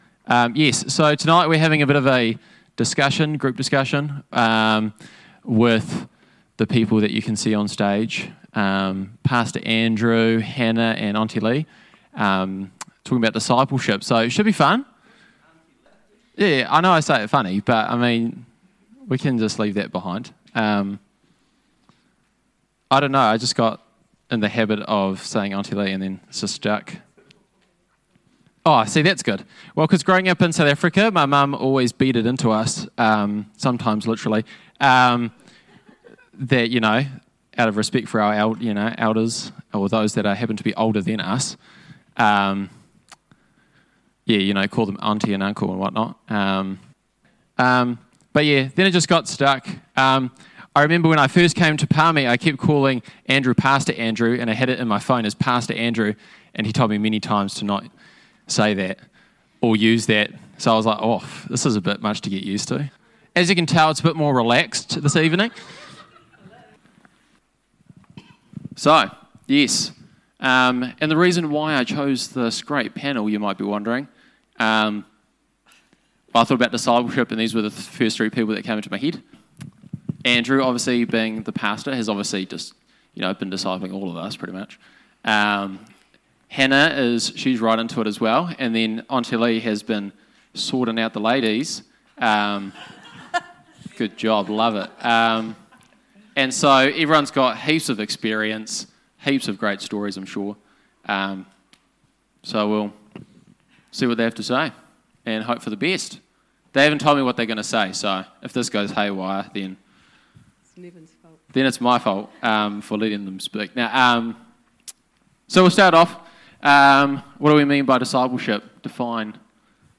Panel Discussion – Discipleship – Part One